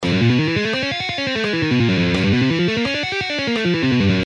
Legato Guitar Exercise
Lessons-Guitar-Mark-Tremonti-Legato-Exercises-6.mp3